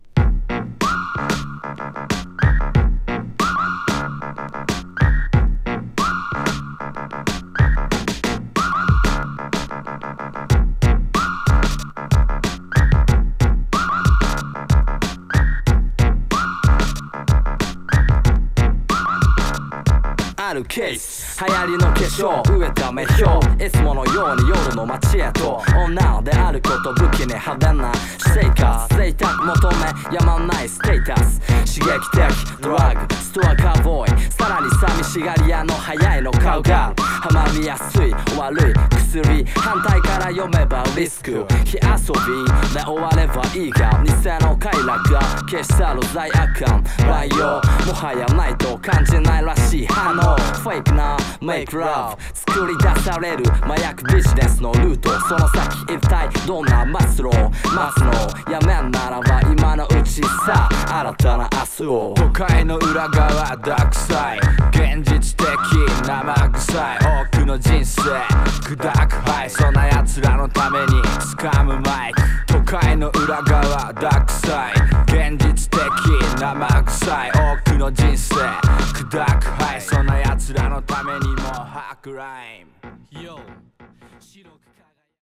警報機のようなSEが行き交うドープ・サウンドに、相変わらずの固い韻踏みスタイル!